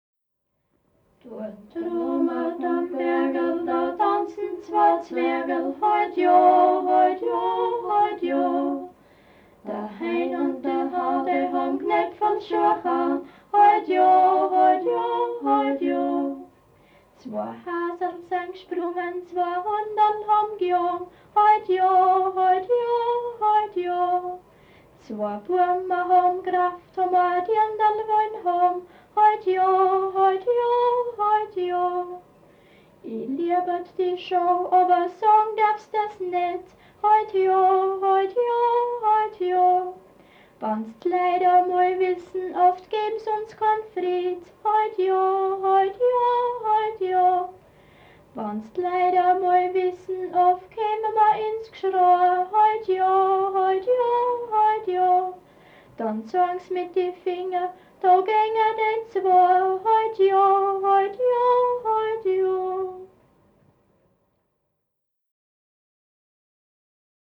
Jodler, Jodler-Lied, Gstanzl und Tanz
Folk & traditional music